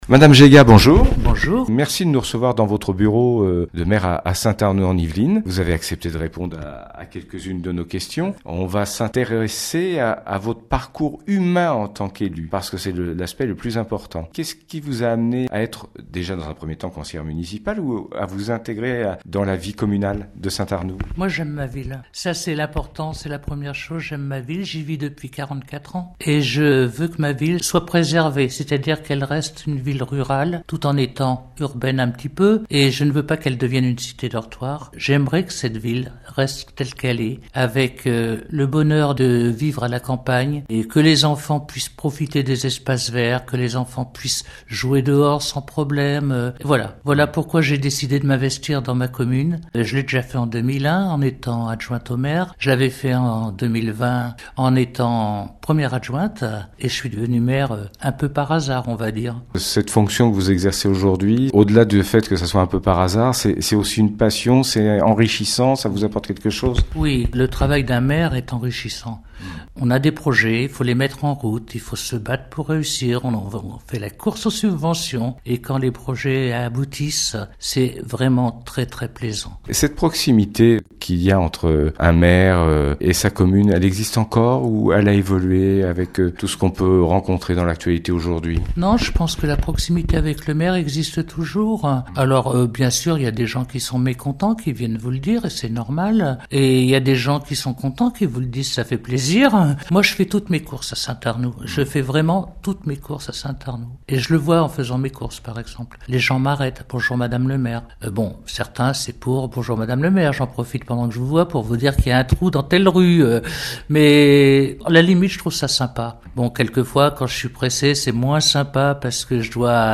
Interview Madame Jegat Maire de Saint Arnoult en Yvelines - Radio RVE